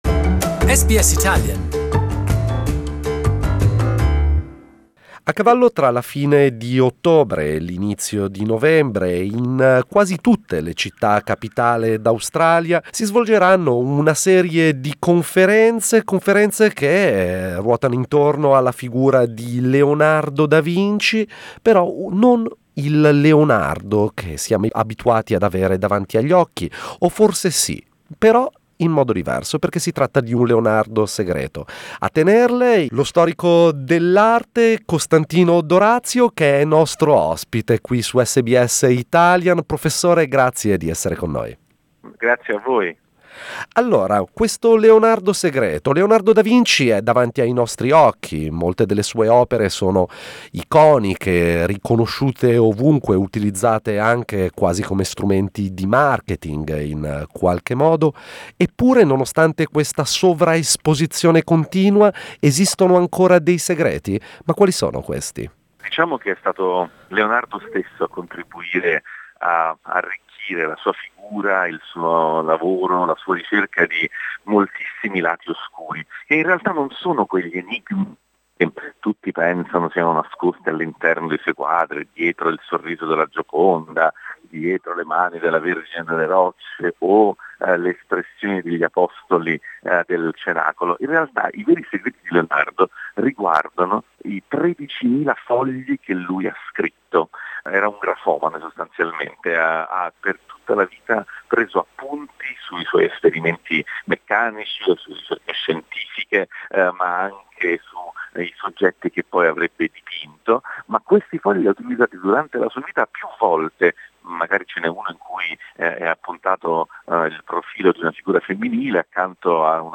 The audio interview and the article are in Italian Pittore, scultore, inventore, scienziato, musicista, architetto: Leonardo da Vinci è considerato uno dei più grandi geni di tutti i tempi.